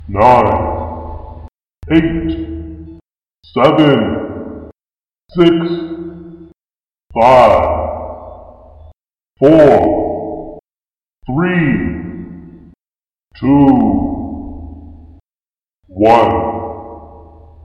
countdownReverb
Category 🎮 Gaming
console count down game sega video sound effect free sound royalty free Gaming